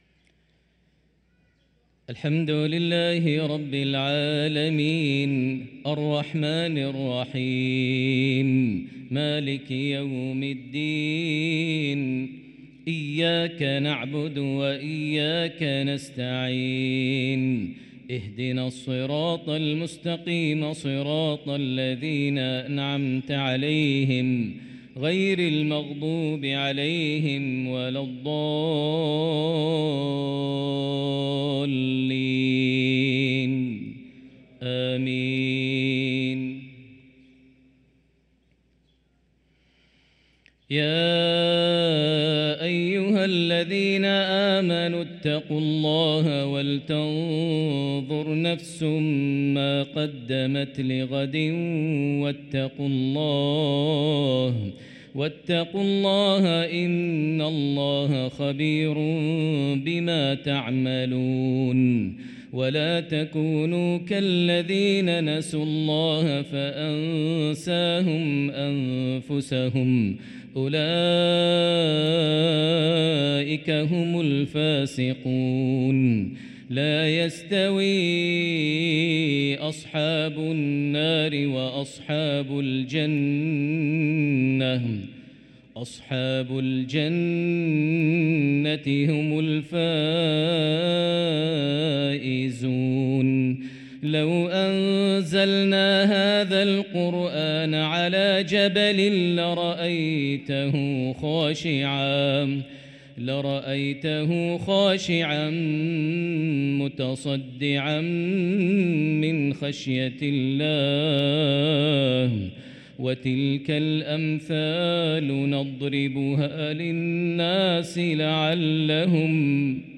صلاة المغرب للقارئ ماهر المعيقلي 17 ربيع الأول 1445 هـ
تِلَاوَات الْحَرَمَيْن .